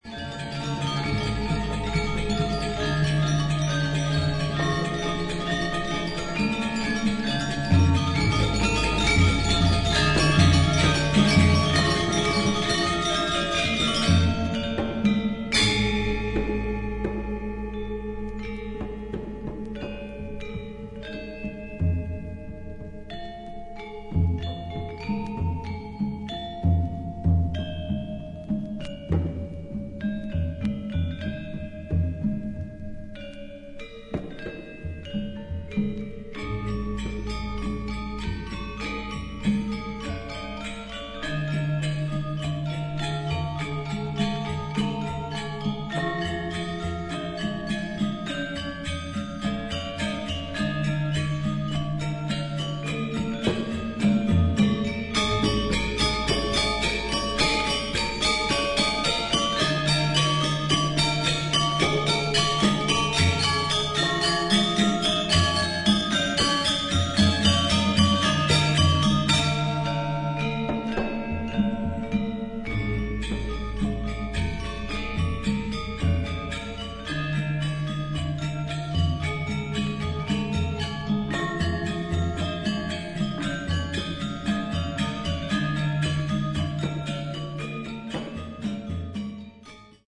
ARTIST : GAMELAN GONG SEKAR ANJAR, GENDER WAJANG QUARTET, DR. MANTLE HOOD